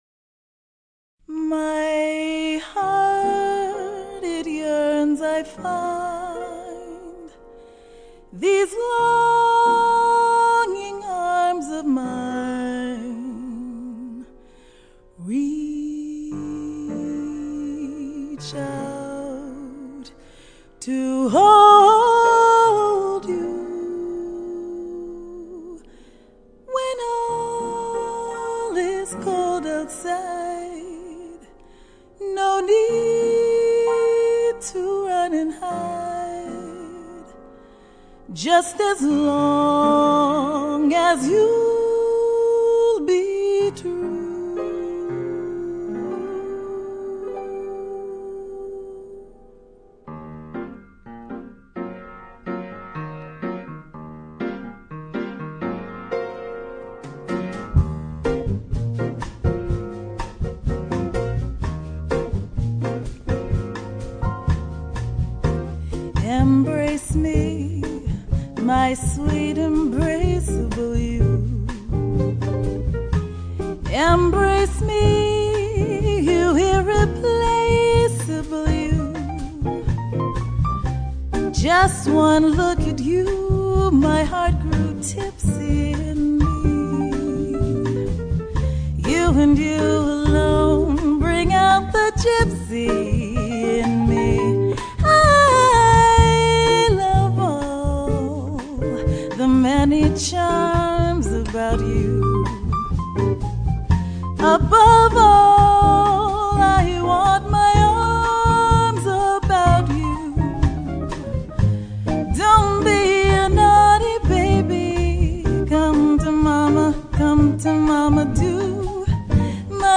piano, bass and drums